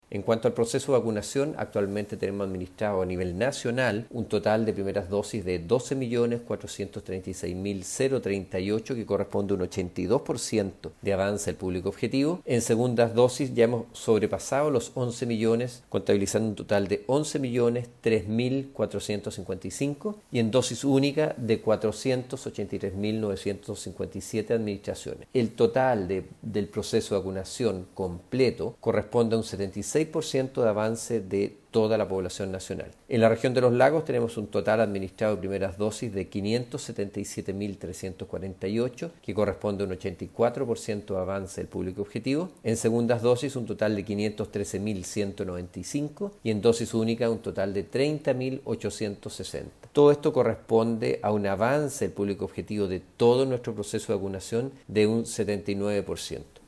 Respecto al proceso de vacunación la autoridad de salud, indicó: